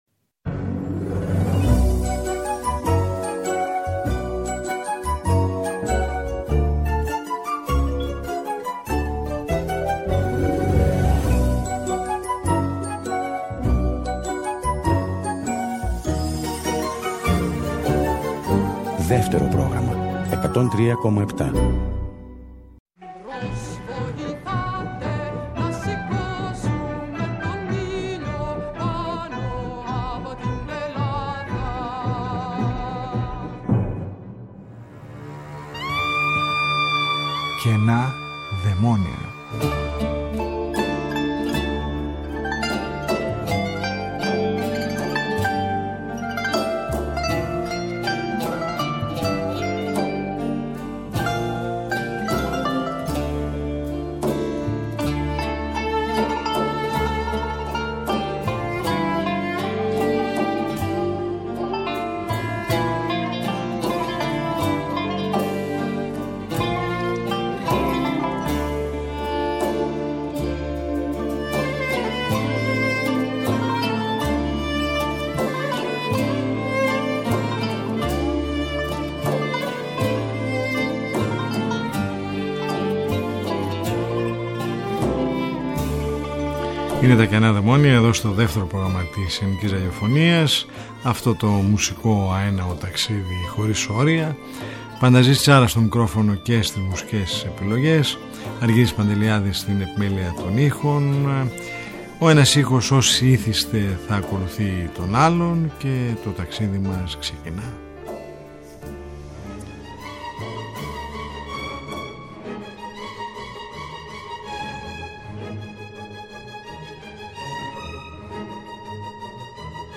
Μια ραδιοφωνική συνάντηση κάθε Σάββατο στις 10:00 που μας οδηγεί μέσα από τους ήχους της ελληνικής δισκογραφίας του χθες και του σήμερα σε ένα αέναο μουσικό ταξίδι.